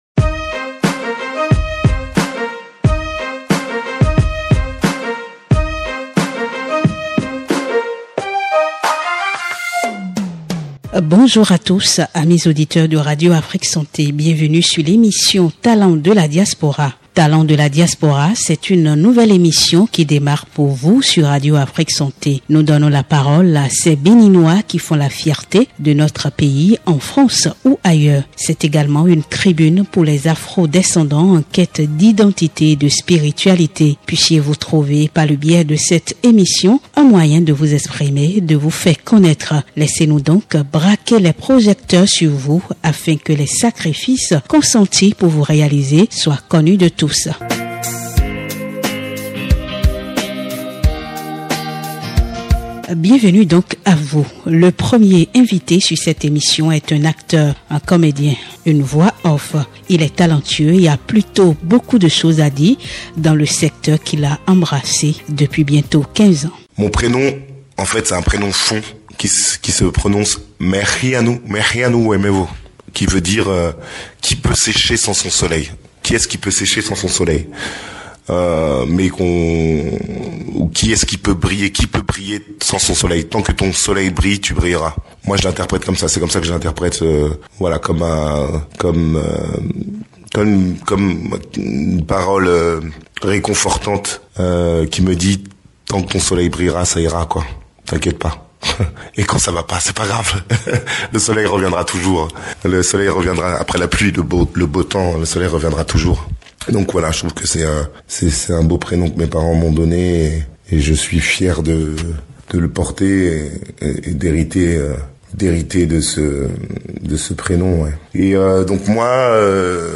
Dans cette interview palpitante, Radio Afrique Santé vous propose d’aller à la découverte d’un acteur pluridimensionnel.